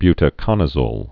(bytə-kŏnə-zōl)